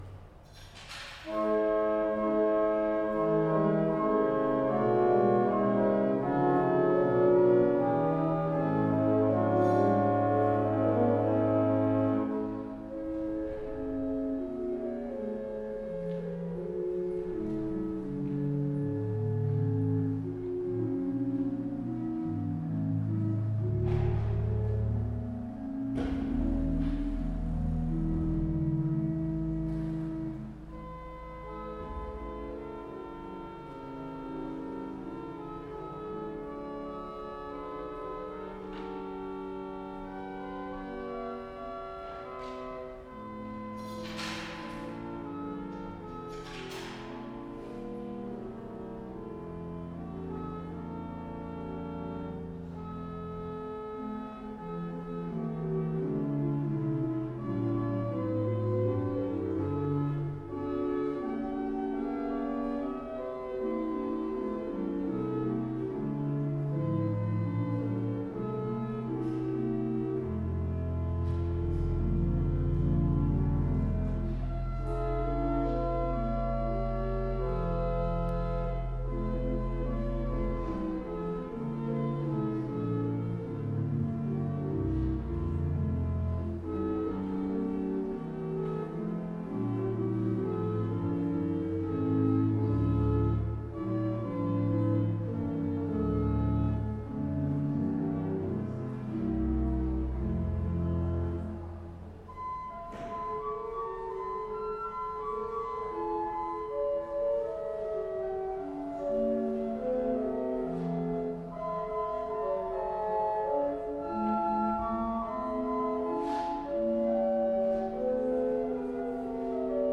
2 manuály 13 rejstříků
Zvukové představení varhan (2025)
radslavice-predstaveni-varhan.mp3